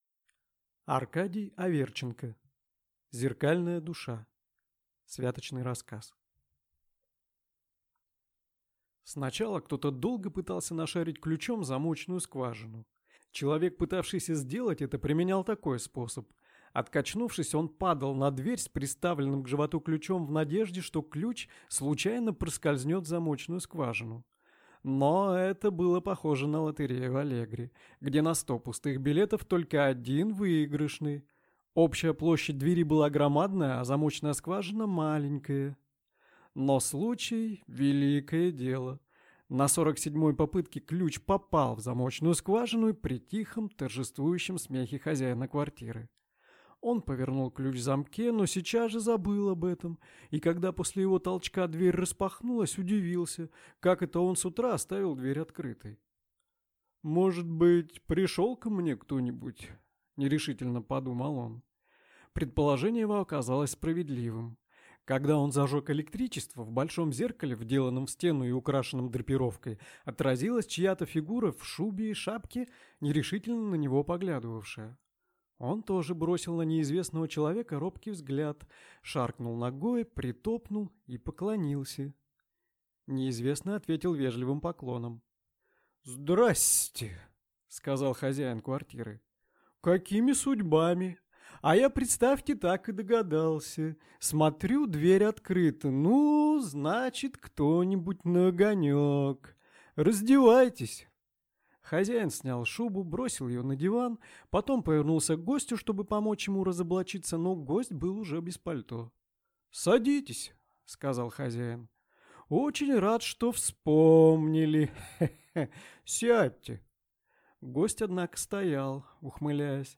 Аудиокнига Зеркальная душа | Библиотека аудиокниг